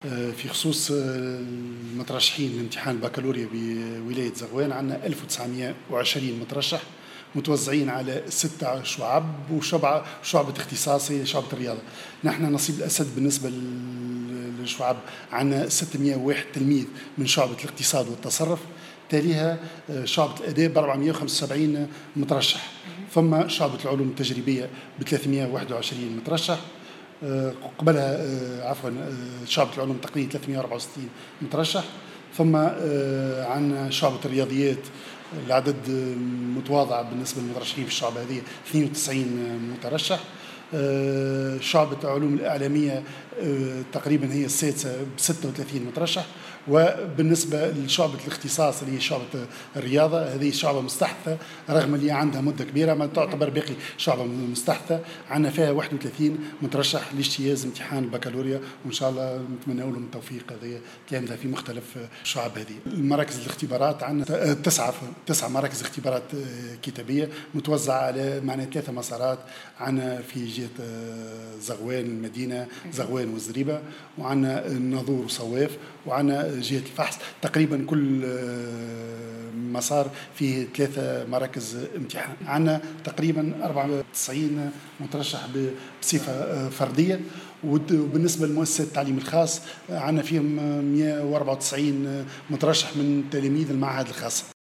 وأضاف الحاج يحي في تصريح لمراسلتنا بالجهة أن 194 مترشحا عن المعاهد الخاصة و94 مترشحا بصفة فردية .